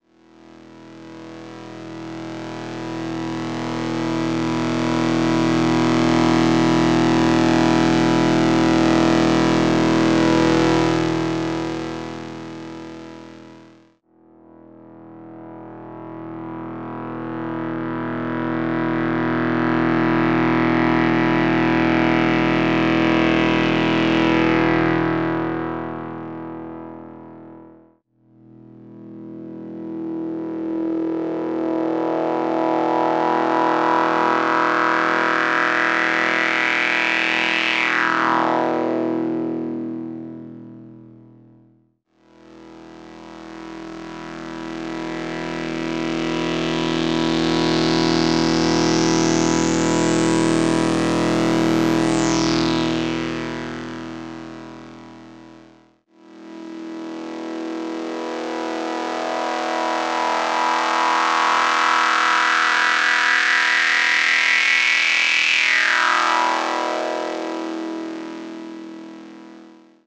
warm, classic sound combined with a modern feature set, built into a low-profile, high-quality 10hp eurorack module.
demo 1: pad sweeps
input: 3x part harmony w/- sawtooth & pulse waves.
cv: doepfer a-140 adsr to ade-20 cutoff frequency.